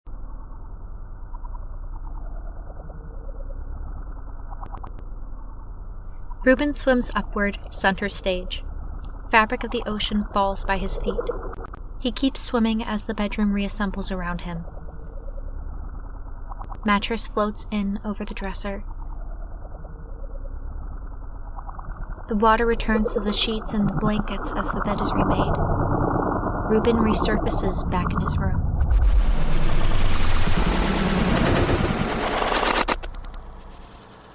This is the transition between scenes 4 and 5, Reuben’s (our main character) fall from a parachute in the sky to the bottom of the sea.